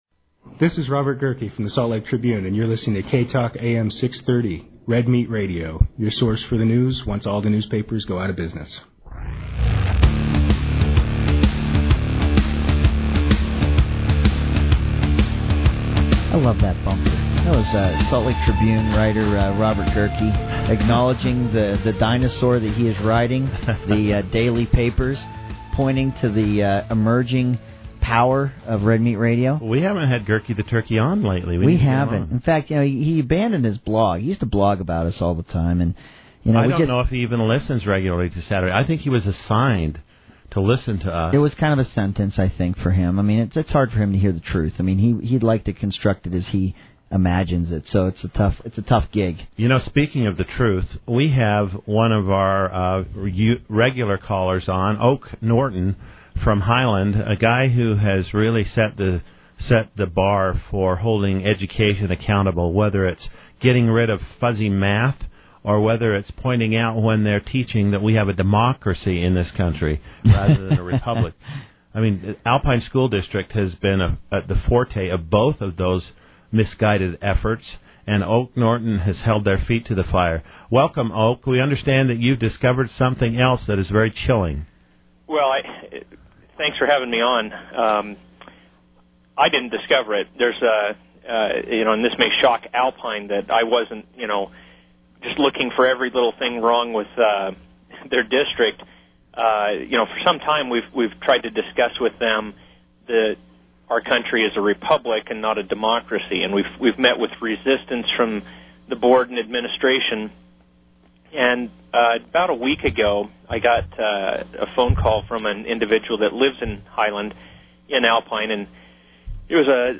Red Meat Radio Interview on Alpine School District's Indoctrination
This morning I was on air with Senator Howard Stephenson and Representative Greg Hughes on their radio program, “Red Meat Radio” discussing how the administration at Alpine School District (ASD) is promoting a change of government from a Republic to a Democracy.